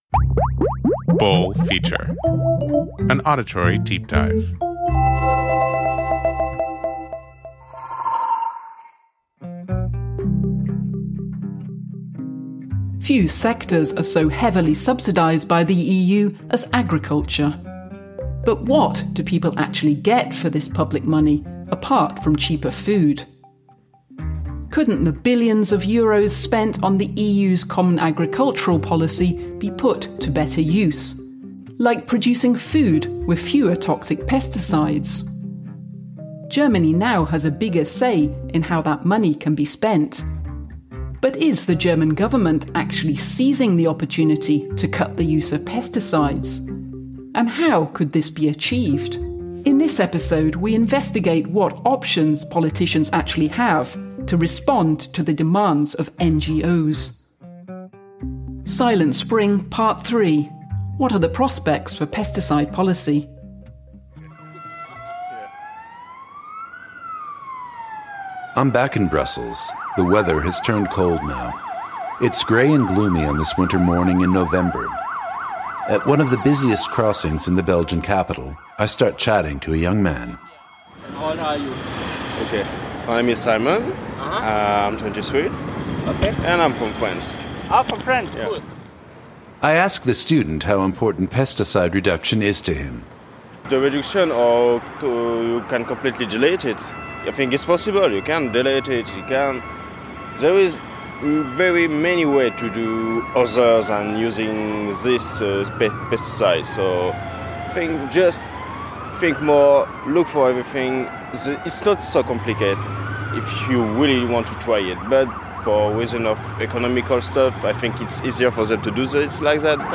The conducted interviews and original sound recordings come from Europe (Berlin, Brussels and the region of Brandenburg).